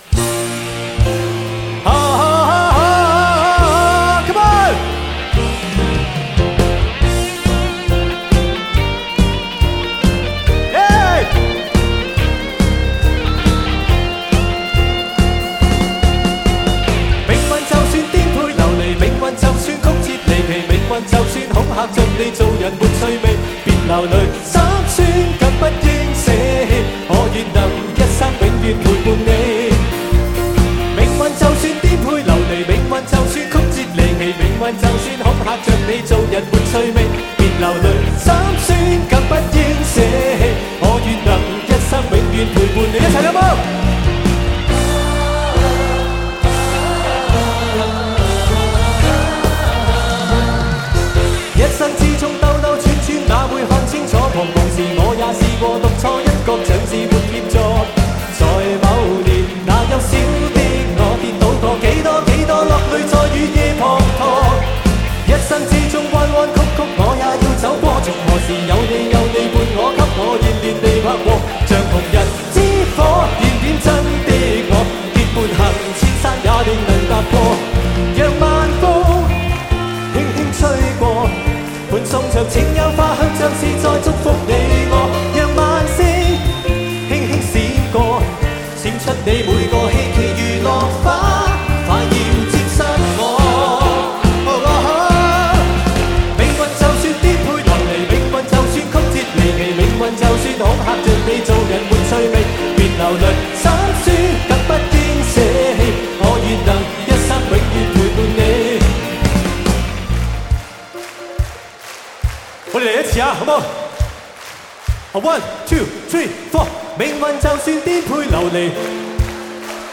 24bit／96kHz光纤线材录音 顶级声效质素淋漓尽现